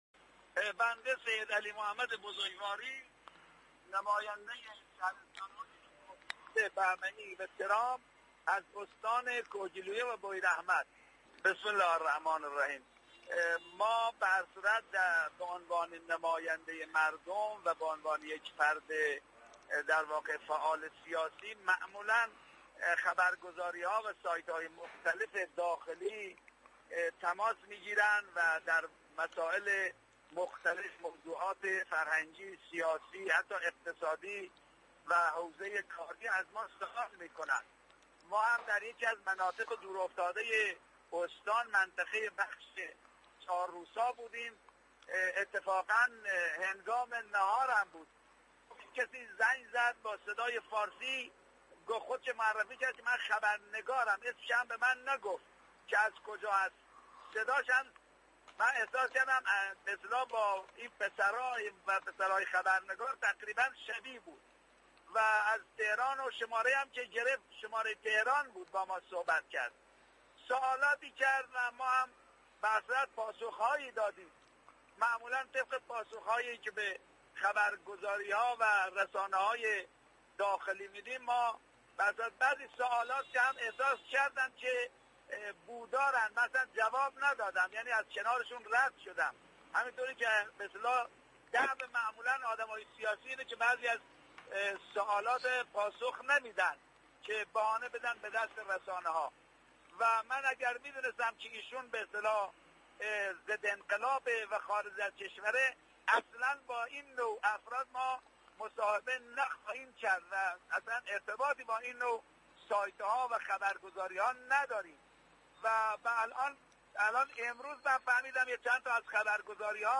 فایل صوتی مصاحبه بولتن نیوز با بزرگواری و اعلام انزجار وی از مصاحبه با این ضد انقلاب در ادامه می آید.